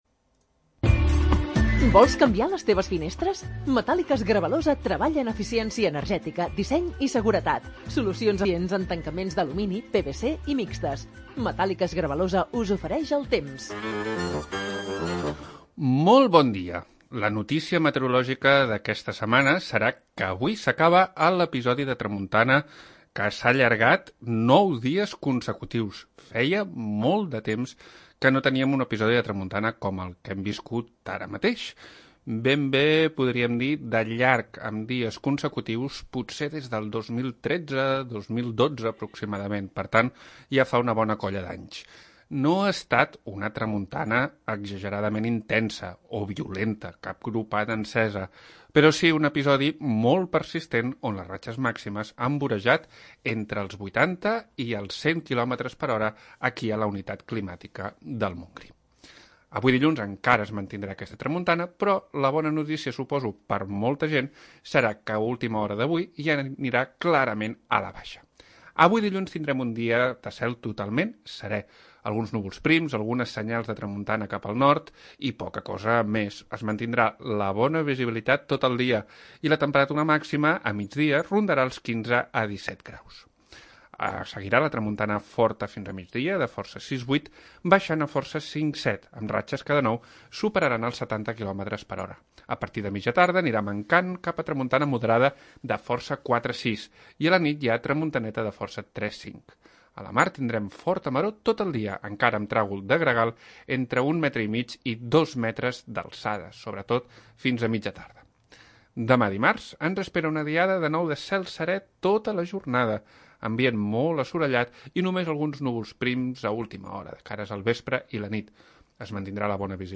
Previsió meteorològica 22 de Març de 2021